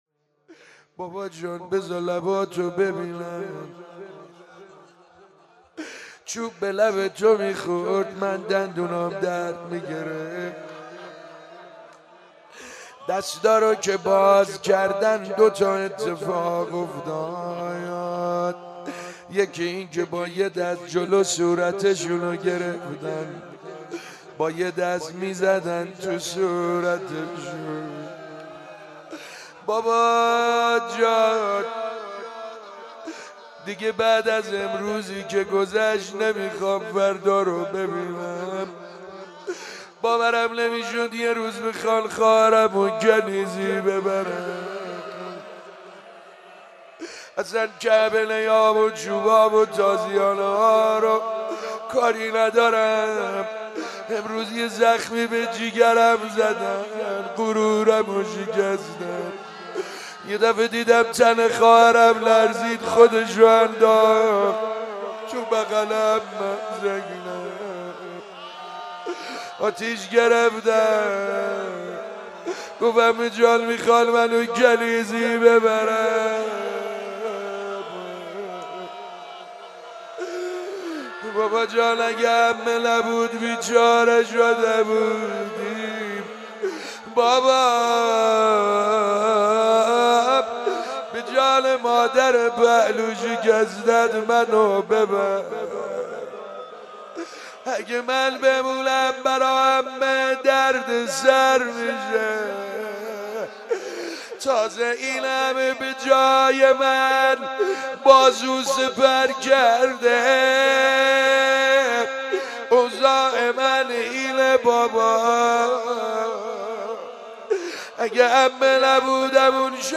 مداحی
در مهدیه امام حسن مجتبی(ع) برگزار گردید.
دعا ومناجات روضه سینه زنی لینک کپی شد گزارش خطا پسندها 0 اشتراک گذاری فیسبوک سروش واتس‌اپ لینکدین توییتر تلگرام اشتراک گذاری فیسبوک سروش واتس‌اپ لینکدین توییتر تلگرام